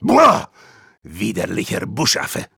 Voice file from Team Fortress 2 German version.
Spy_dominationsniper01_de.wav